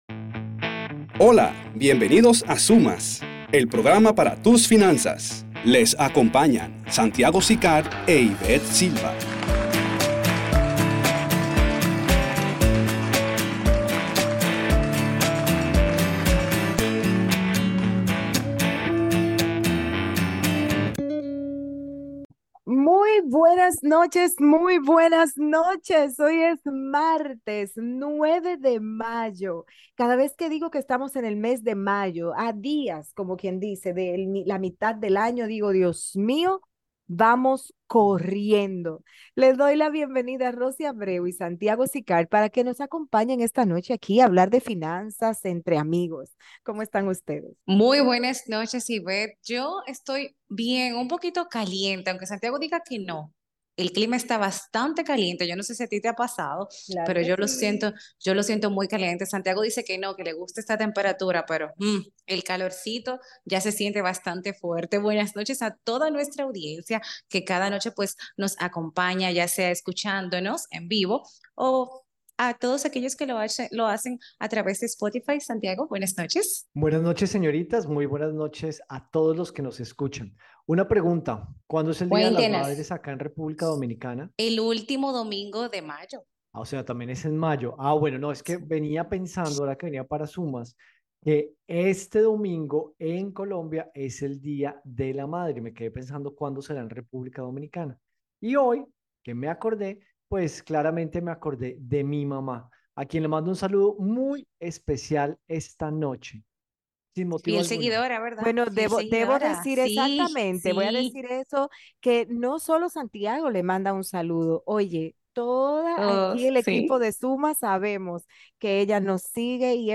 Esucha todo nuestro programa de radio de hoy.